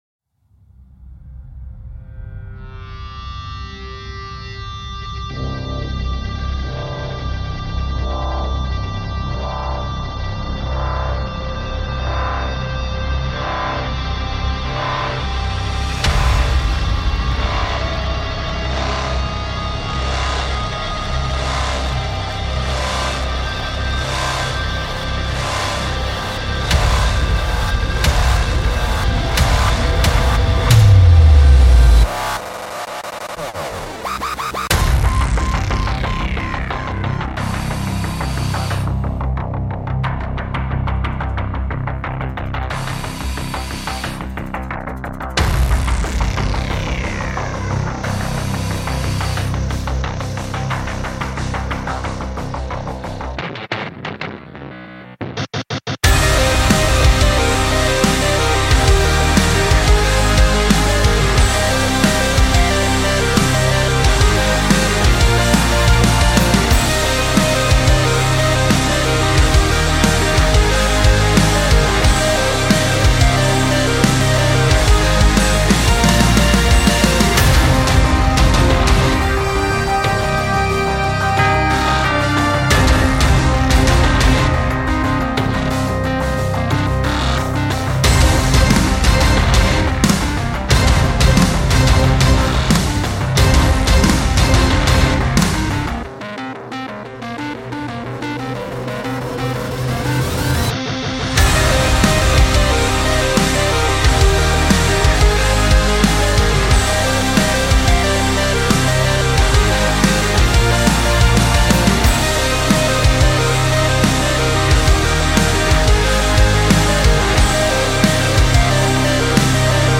Epic Hybrid Action Rock